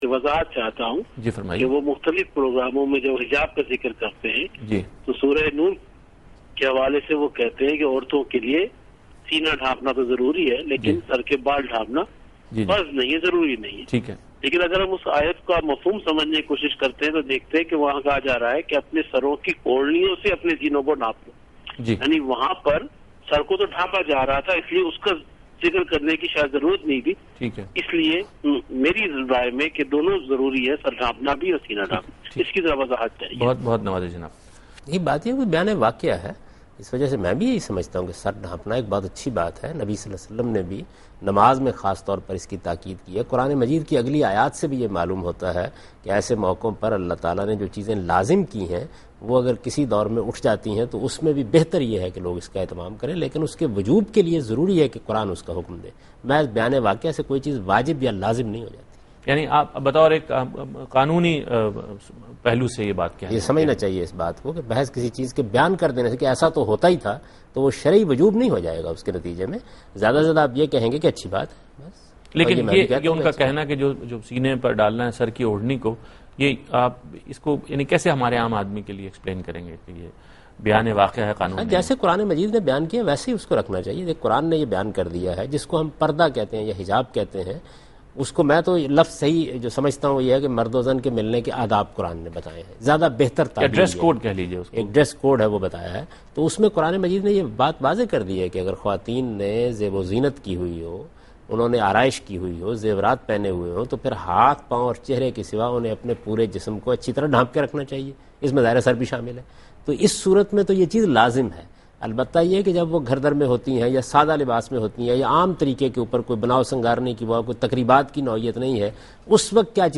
Javed Ahmad Ghamidi answering a question "Head Covering: Desirable or Obligatory?" in program Deen o Daanish on Dunya News.
جاوید احمد غامدی دنیا نیوز پر عورتوں کے دوپٹہ لینے سے متعلق سوال کا جواب دے رہے ہیں۔